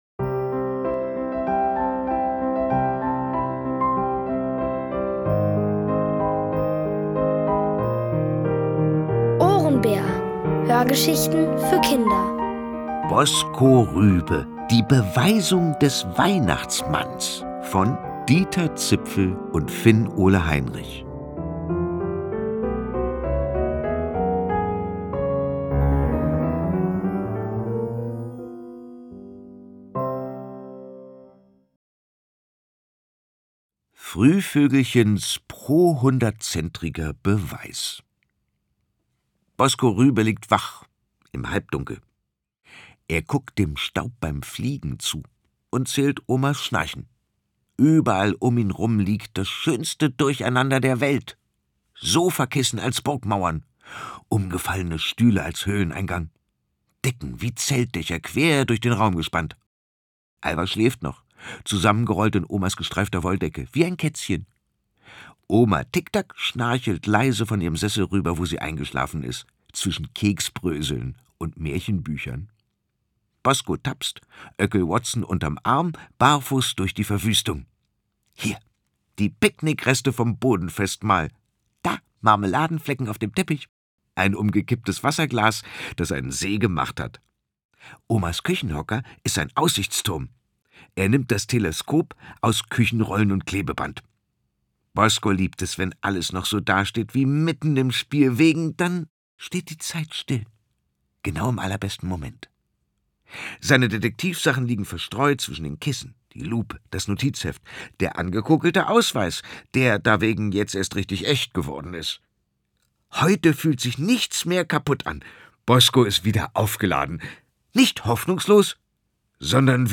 Erzählt von Boris Aljinovic.